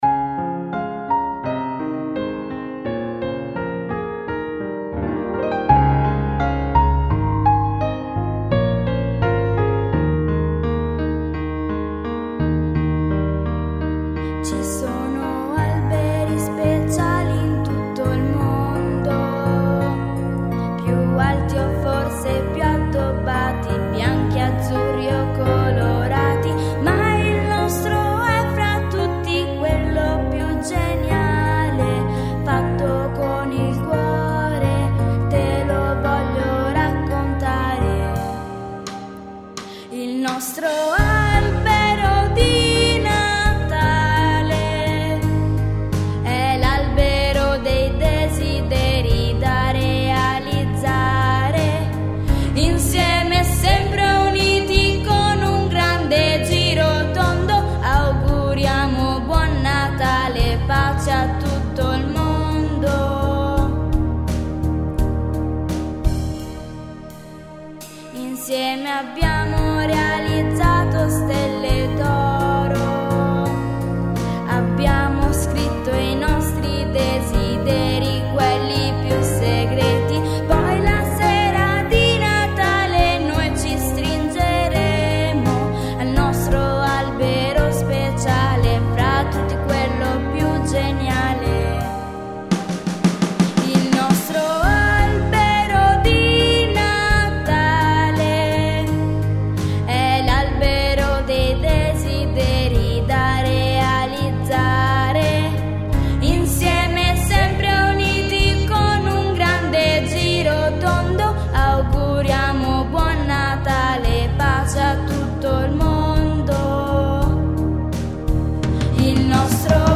canzone